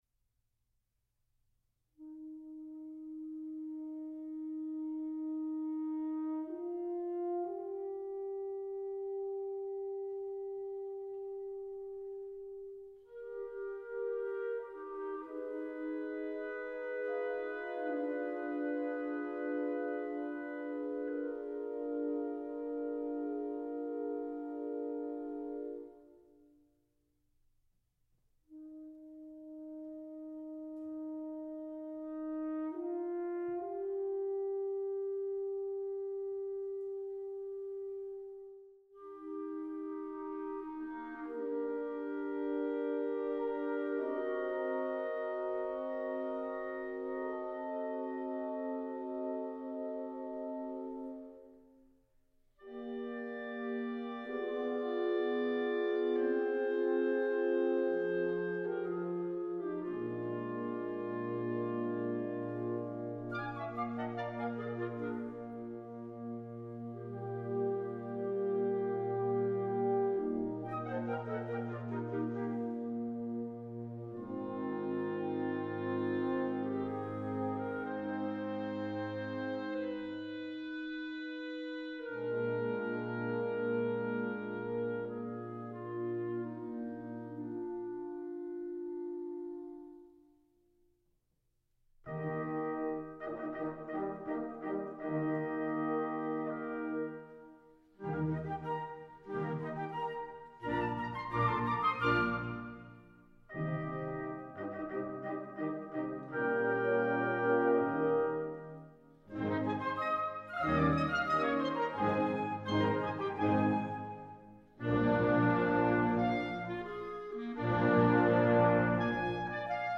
The overture to Oberon, or The Elf King's Oath , performed by the U. S. Navy Band's Concert Band
Performance: U.S. Navy Band